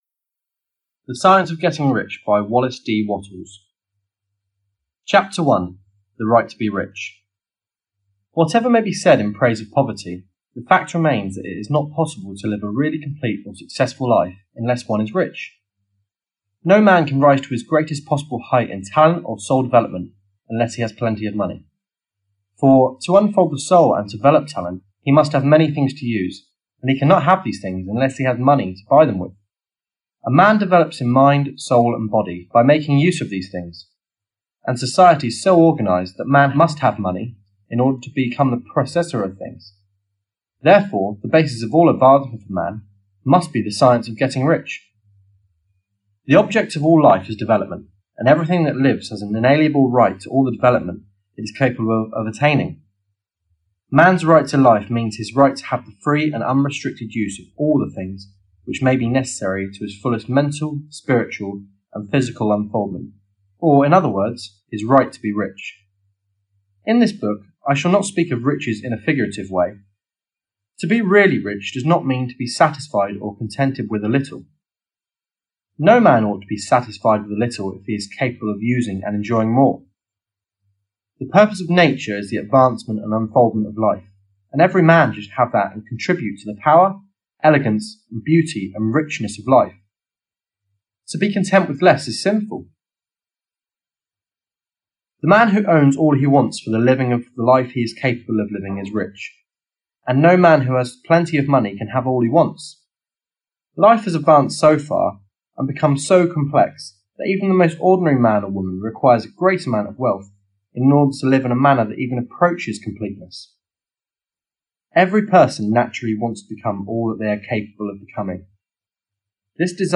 The Science Of Getting Rich (EN) audiokniha
Ukázka z knihy